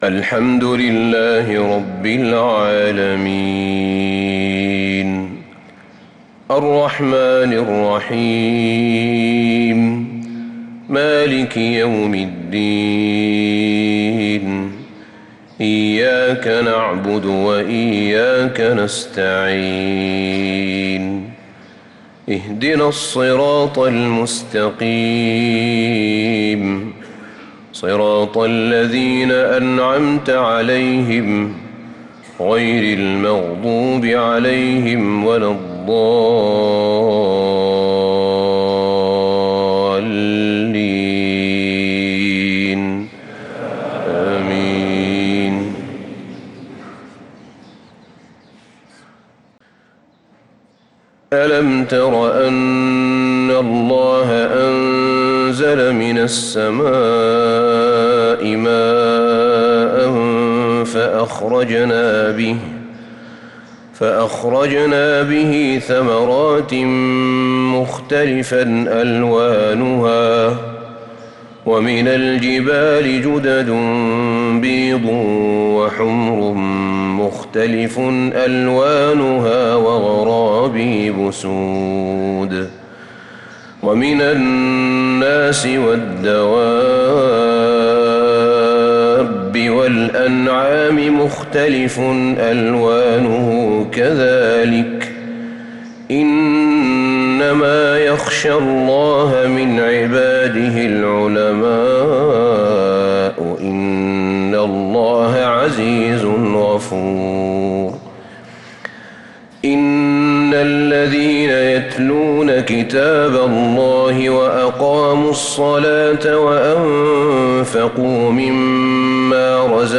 فجر الإثنين 4-8-1446هـ خواتيم سورة فاطر 27-45 | Fajr prayer from Surat Fatir 3-2-2025 > 1446 🕌 > الفروض - تلاوات الحرمين